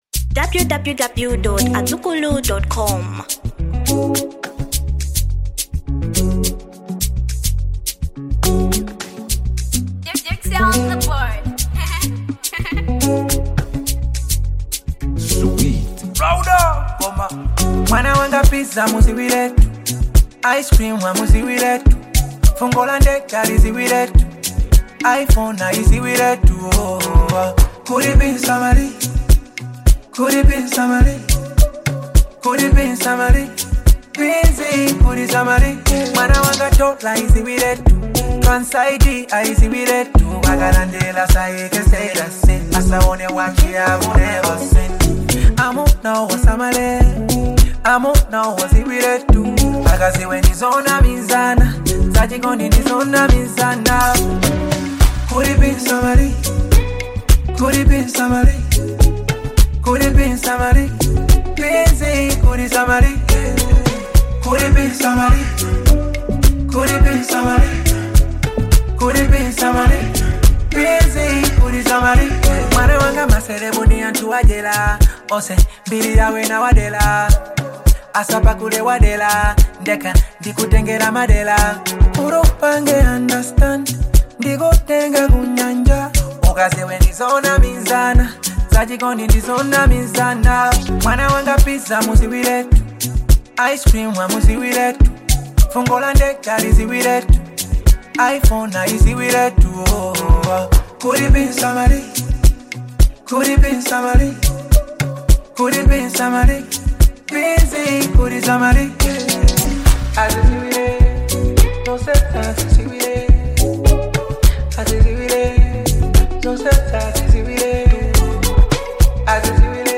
Genre Hip-hop